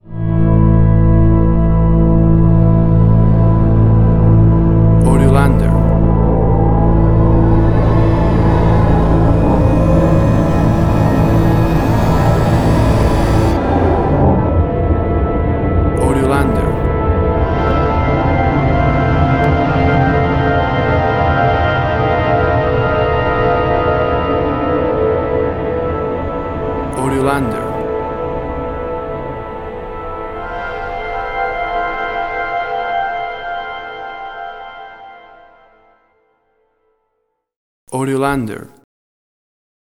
WAV Sample Rate: 16-Bit stereo, 44.1 kHz